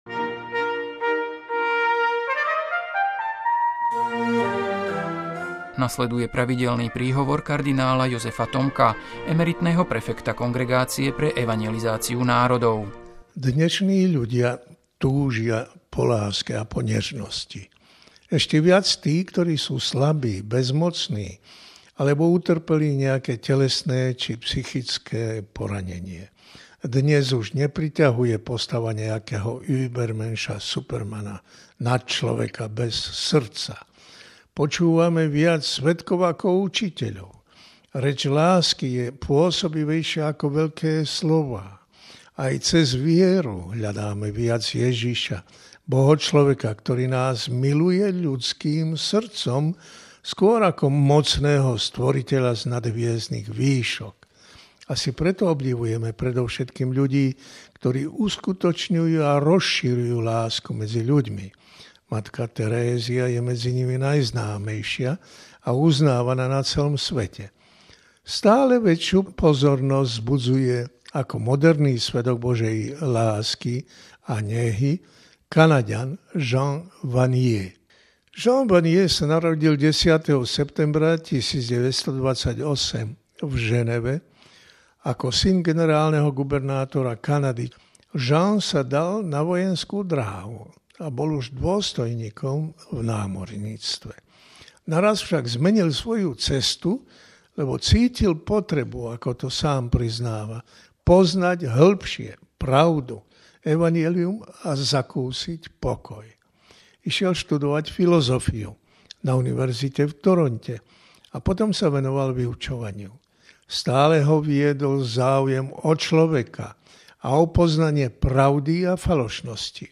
Príhovor kard. Jozefa Tomka: Jean Vanier, svedok Božej nehy